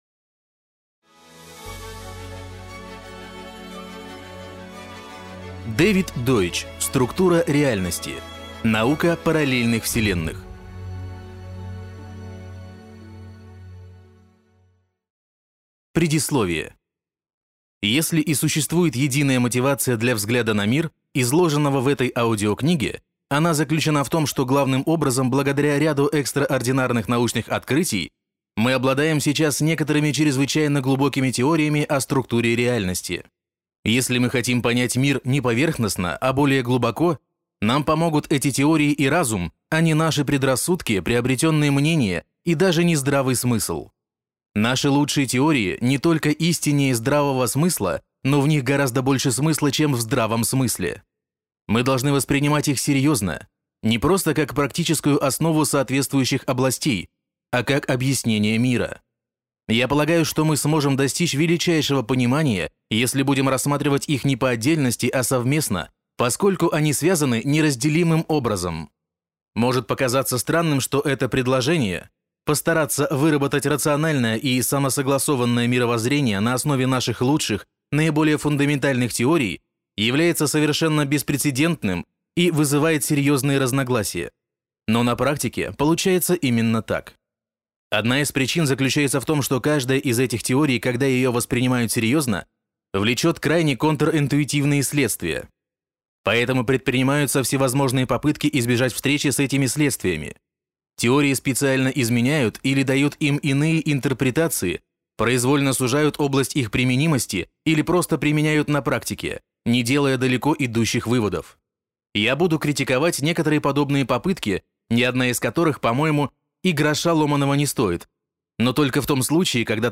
Аудиокнига Структура реальности. Наука параллельных вселенных | Библиотека аудиокниг